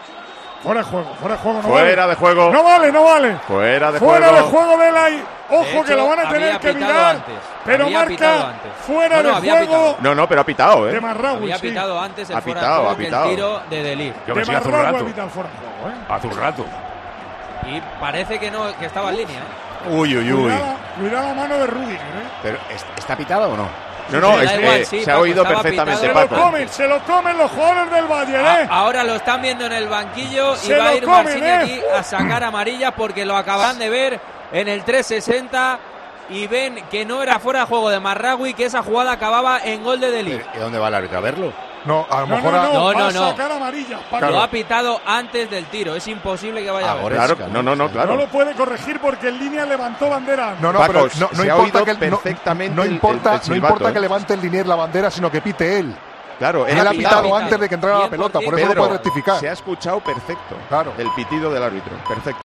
Escucha cómo narramos en Tiempo de Juego la polémica última jugada del partido que provocó el enfado del equipo alemán.